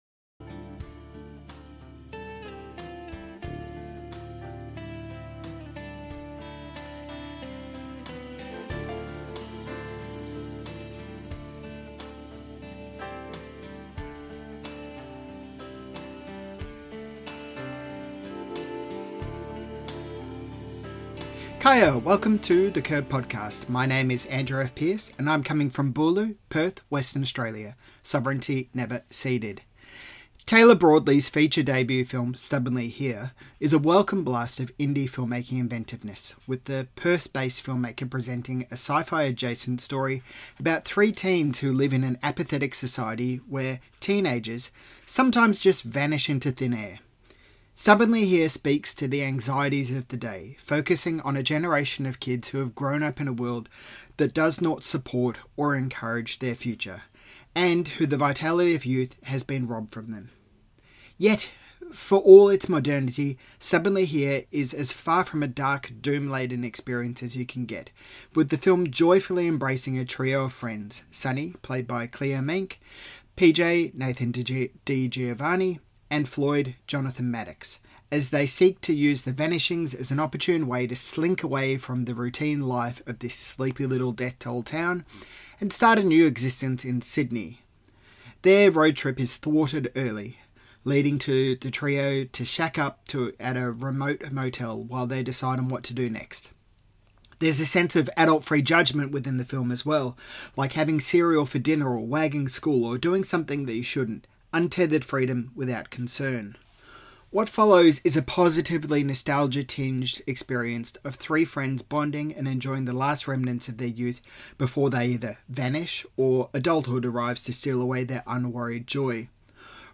Interview - The Curb | Film and Culture